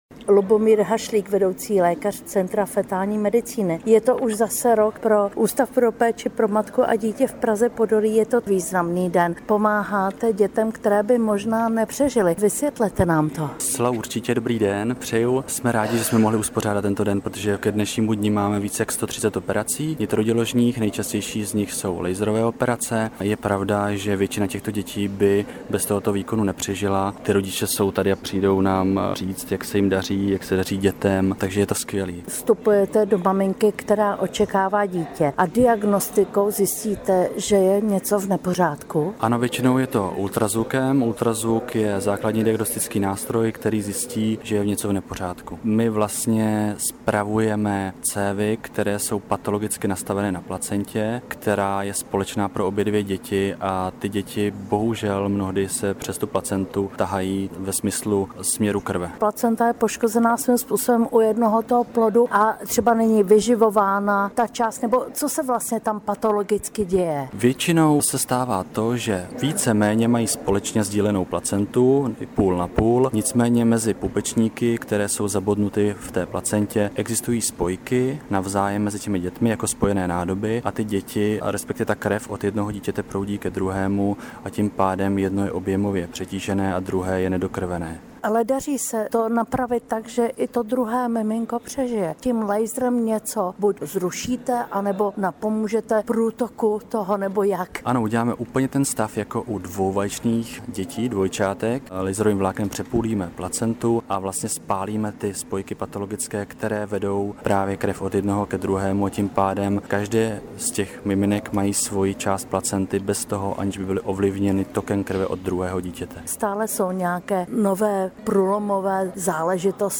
Rozhovor s lékaři o operacích dětí přímo v děloze